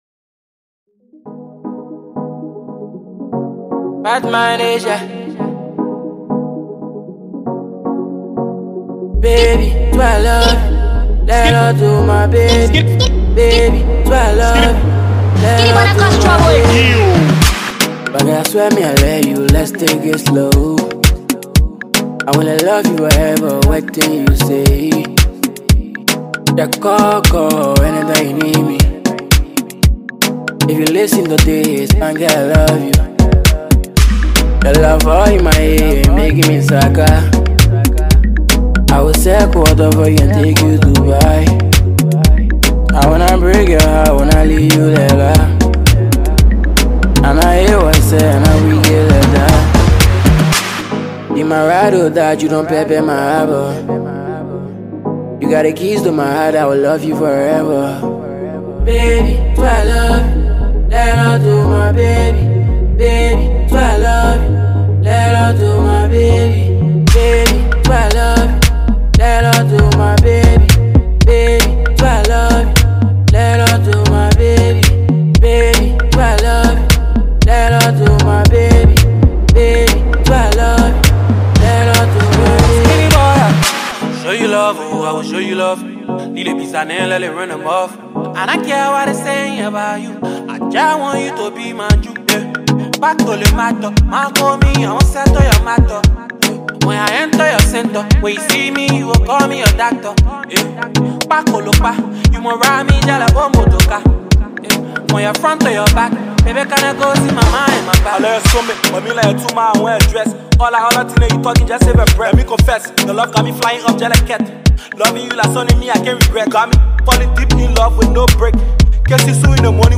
Afrobeats
Cool jam enjoy!